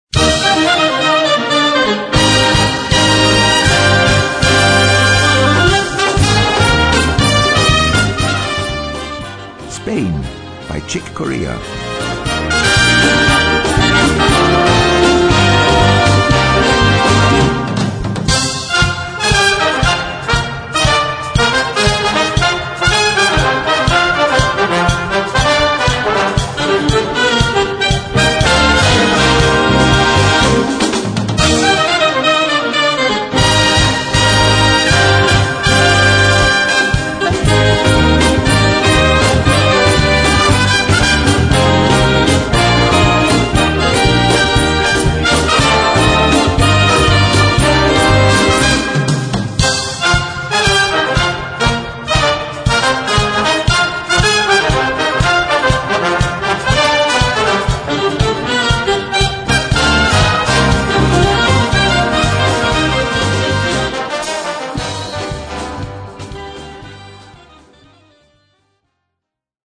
Noten für Blasorchester, oder Brass Band.